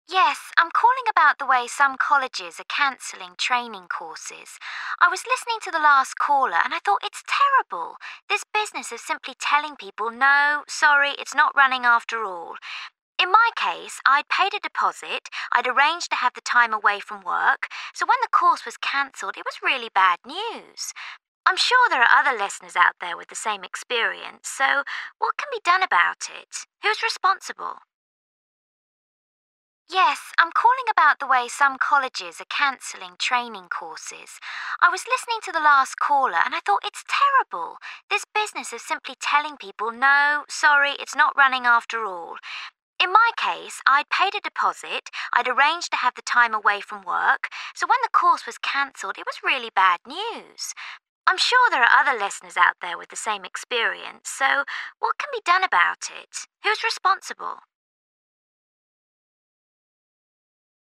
3. You hear a caller on a radio phone-in programme. Why has she phoned?